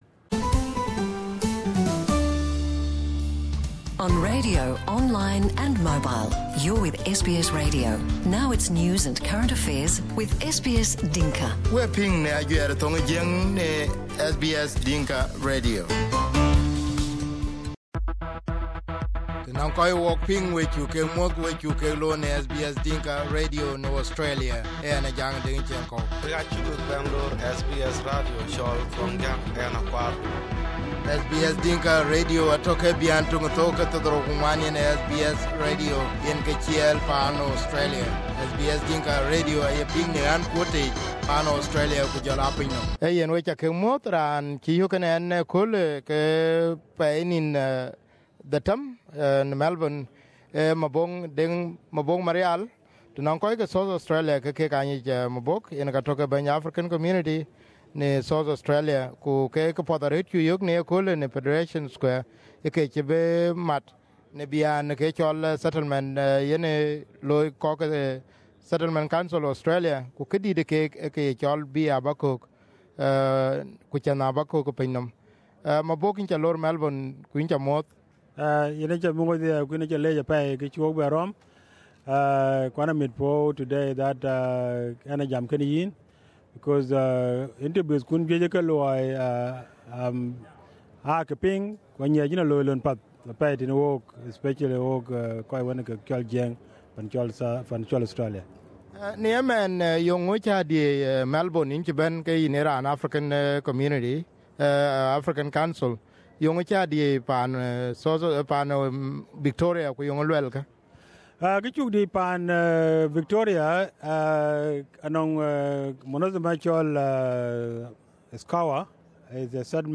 Here is the interview in Dinka